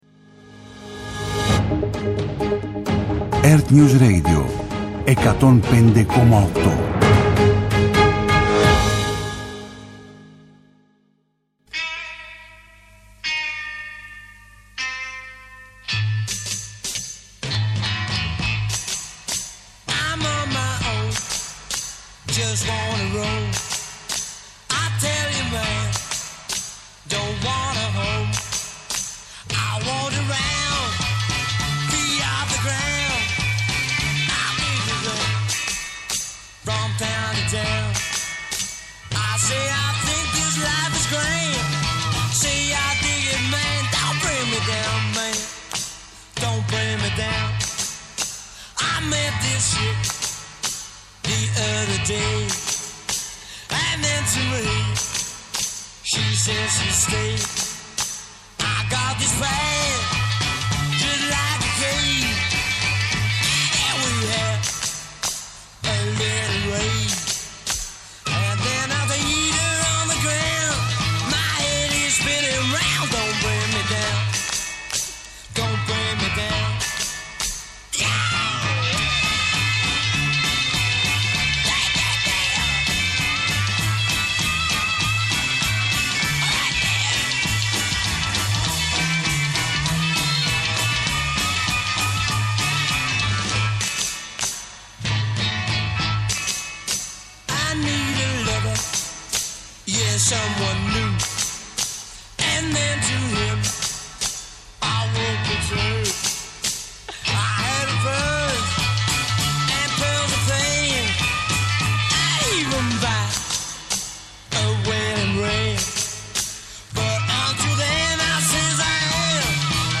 ΜΟΥΣΙΚΗ